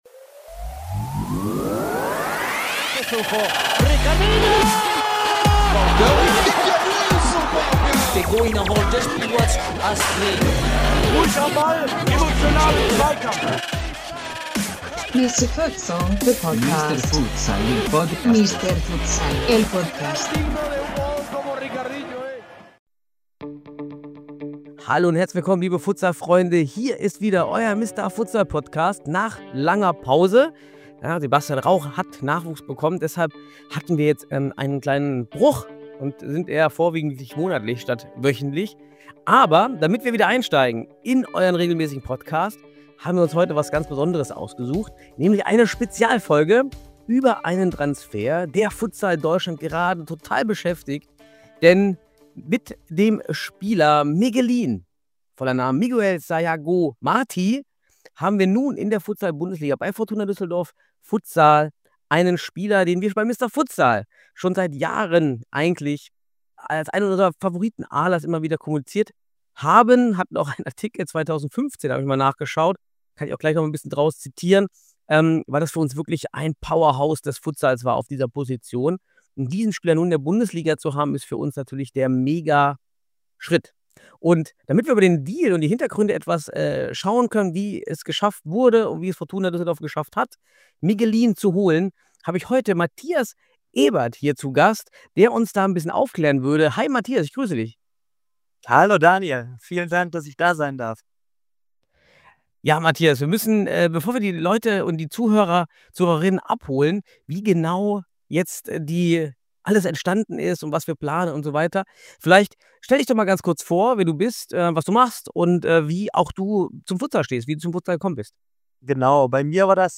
Nach 43 Interview-Folgen wird es Zeit für was Neues: wir testen 2mal20netto unser neues Buddy-Podcast-Format.